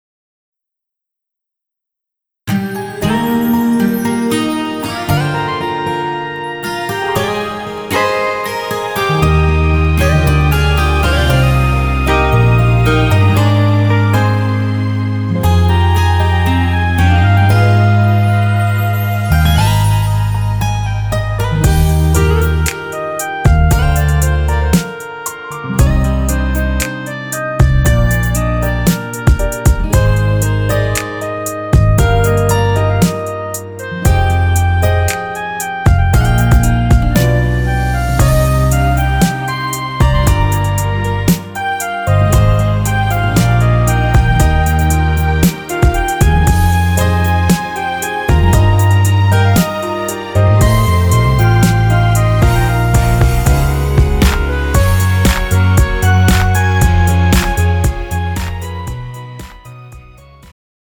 음정 여자-1키
장르 축가 구분 Pro MR